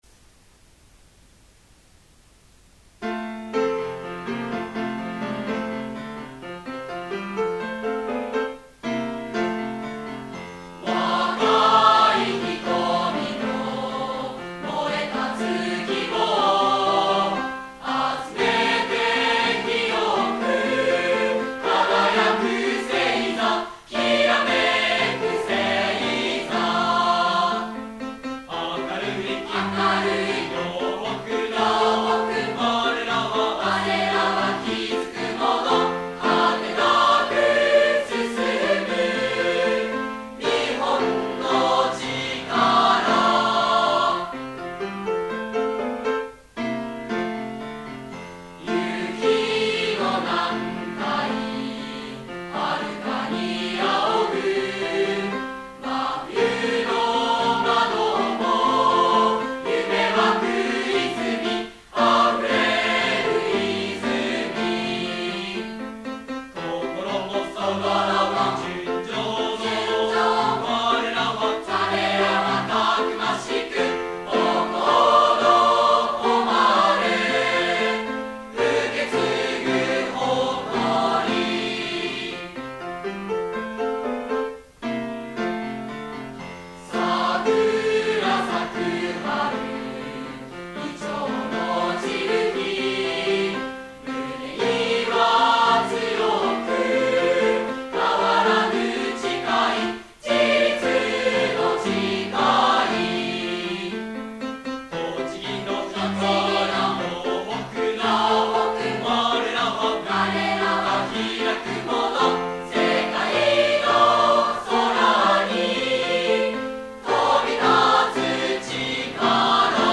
校歌　　　校旗